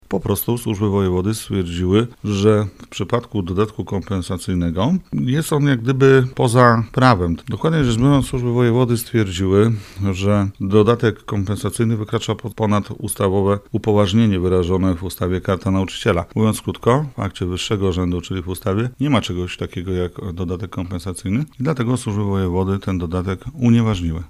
Jacek Budziński, przewodniczący komisji oświaty w ratuszu mówi, że uchylone przepisy od początku wzbudzały kontrowersje: